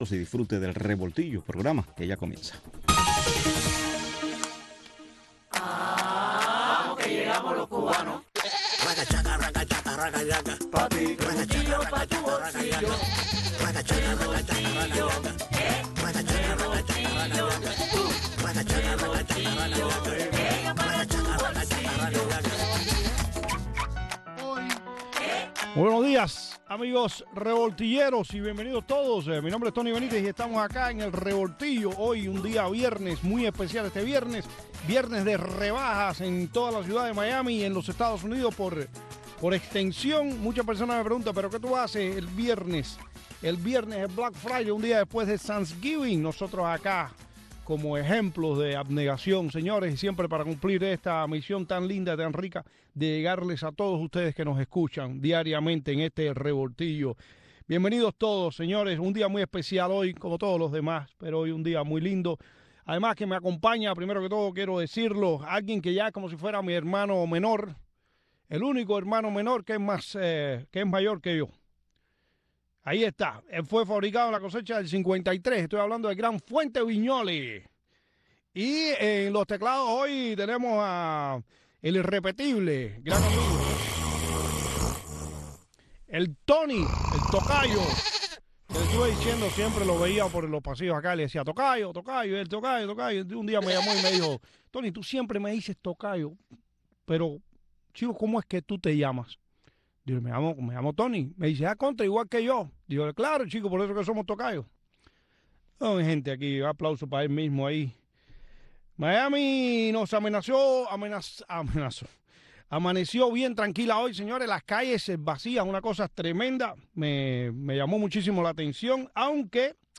entrevistas, anécdotas y simpáticas ocurrencias